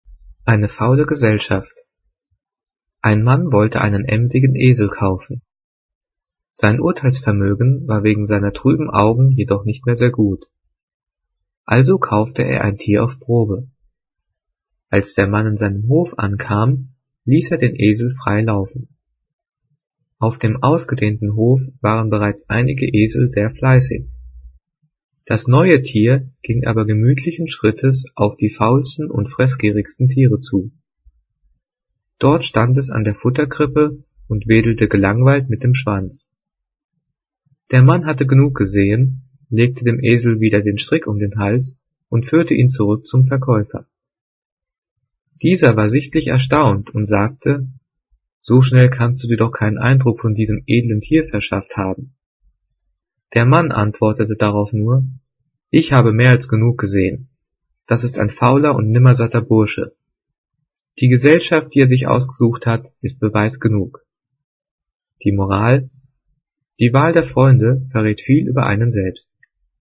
Gelesen:
gelesen-eine-faule-gesellschaft.mp3